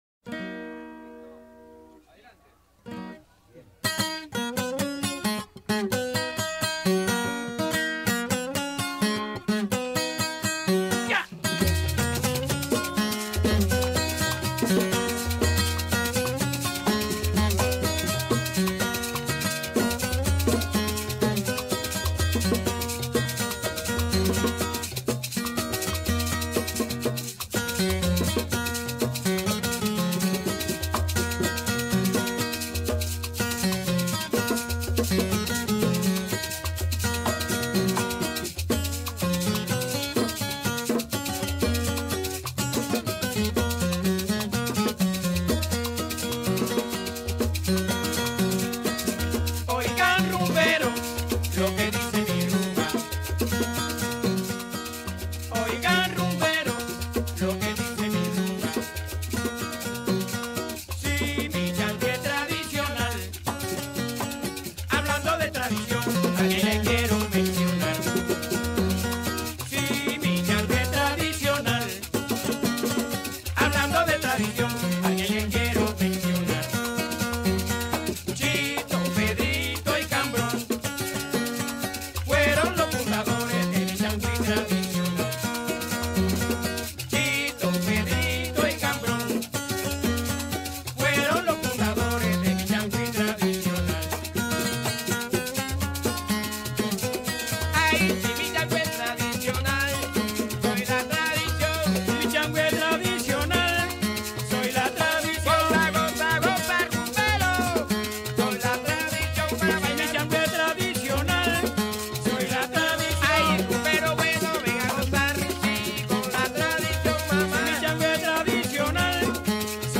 Пара слов про чангуи
Ибо рвать так синкопы и не терять грув на протяжении долгого времени — это и впрямь должно быть где-то в крови.
Вкратце, состав инструментов: маримбула, бонгос, трес, вокал, гуайо (гуиро) — да-да, сами кубаши любят говорить именно «гуайо»!, на котором играют преимущественно ударами вниз, вместо клаве. А клаве в чангуи нет.